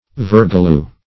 Vergalieu \Ver"ga*lieu\, Vergaloo \Ver"ga*loo\
(v[~e]r"g[.a]*l[=oo]), n. [Cf. Virgouleuse.] (Bot.)